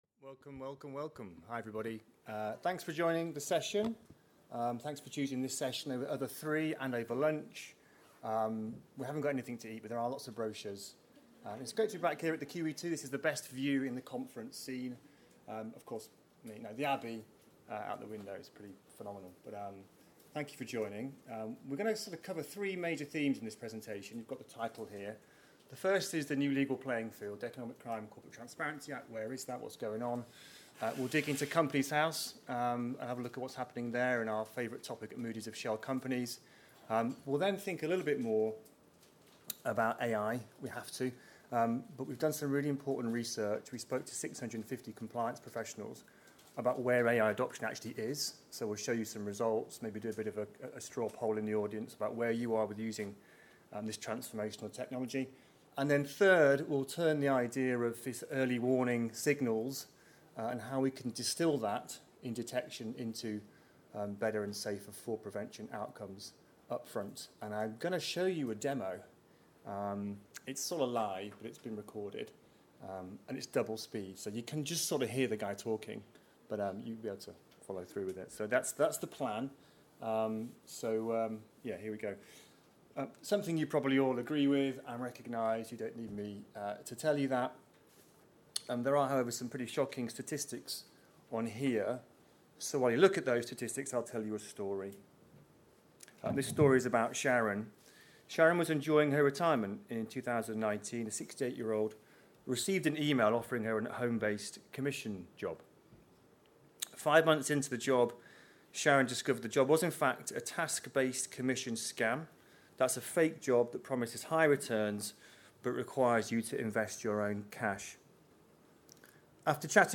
Click the sound wave to hear this presentation in full or click here for a written summary: